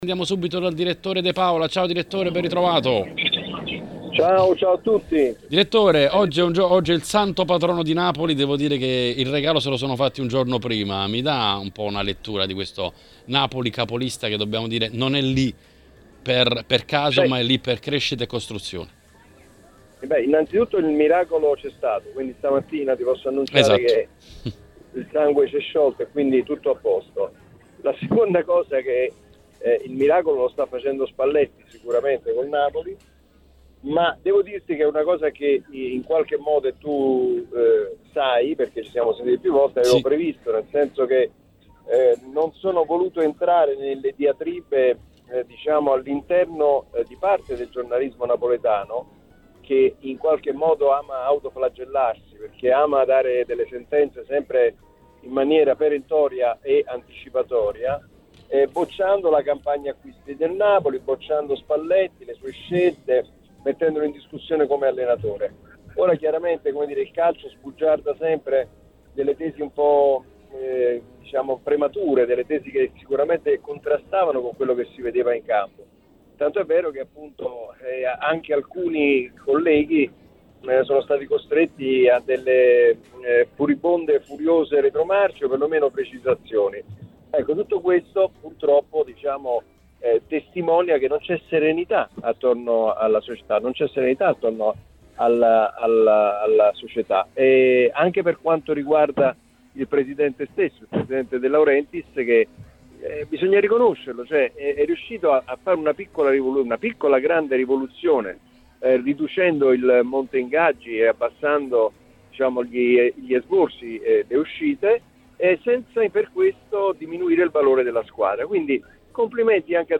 Editoriale